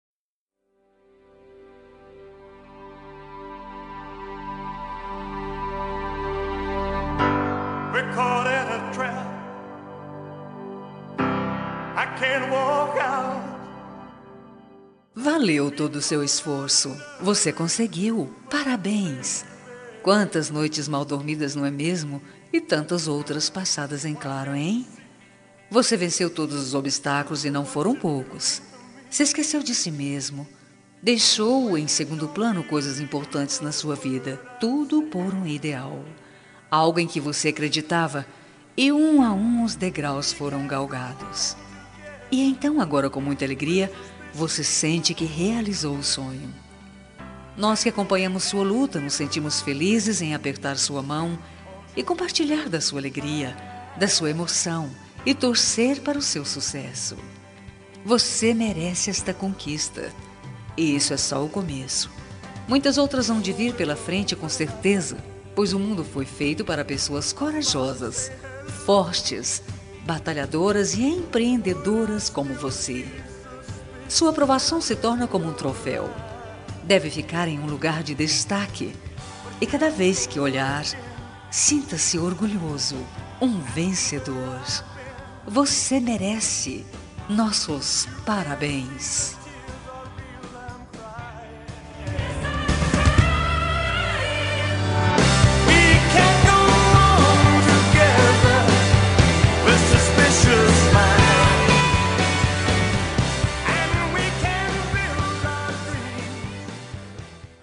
Telemensagem Conquista Vitória – Voz Feminina – Cód: 8150